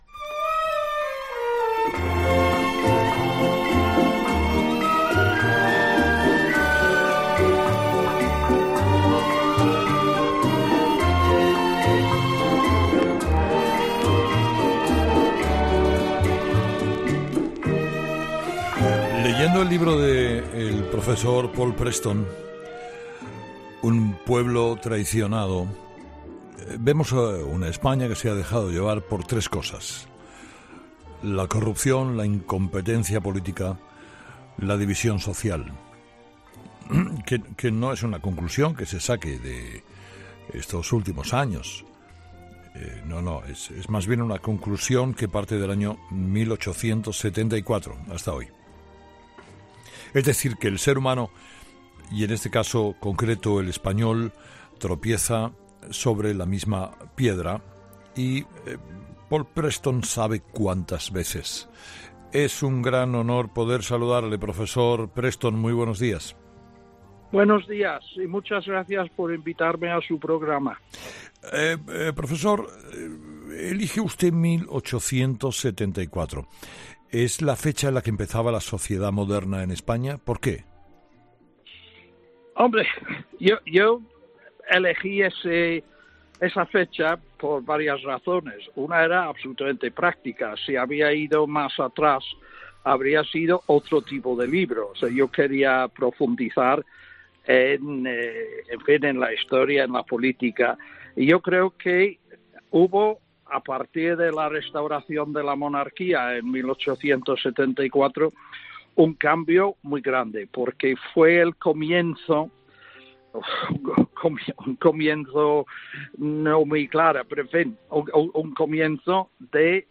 Paul Preston en Herrera en COPE